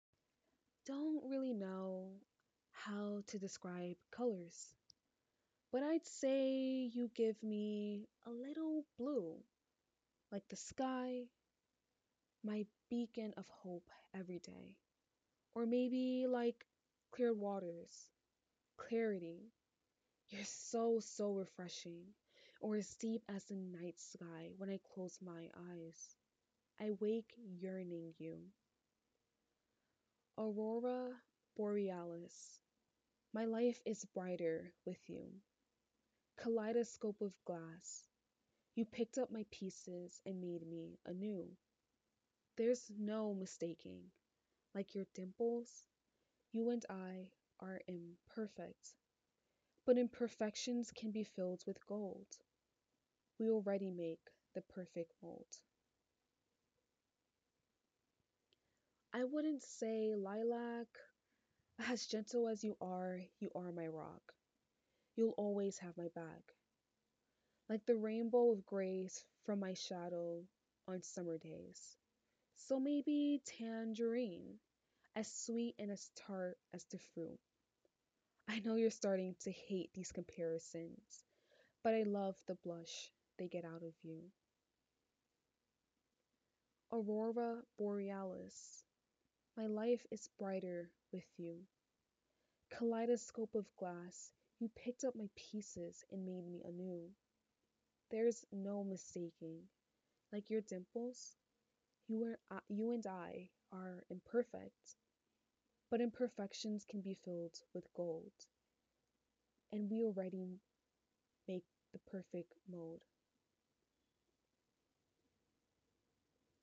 spoke word (demo)